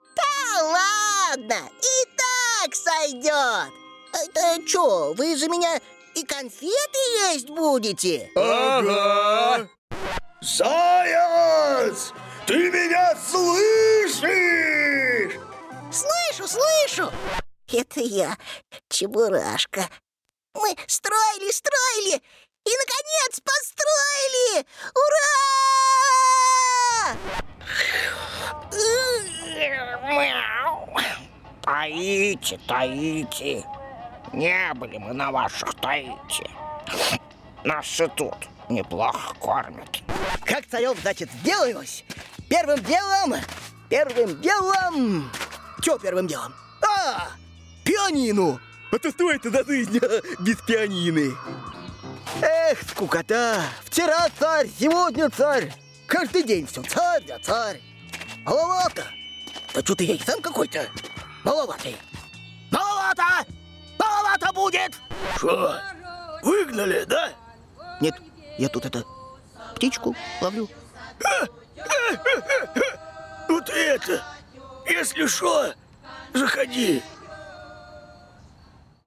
Тракт: Тракт: Микрофон: SHURE SM7B; Пред: LONG Voice Master; Карта (АЦП): Steinberg UR22mkII
Необычный, нестандартный.